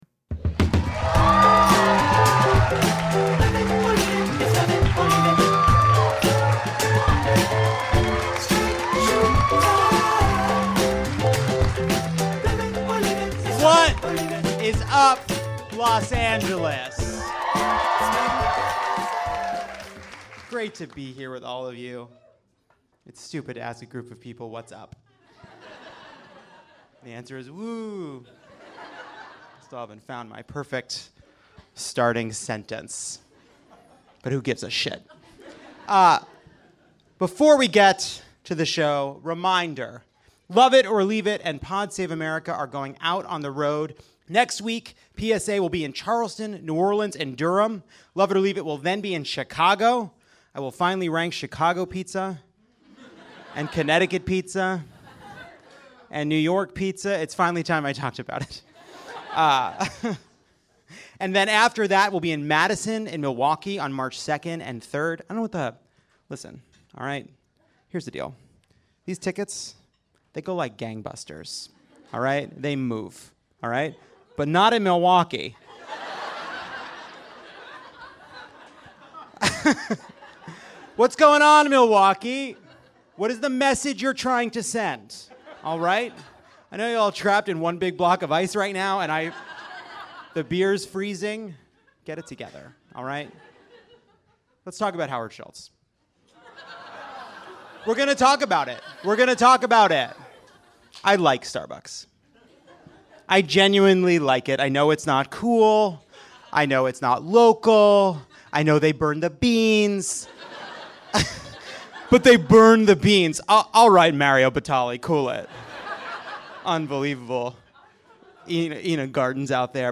Then the panel quizzes the audience on Trump's approach to Venezuela.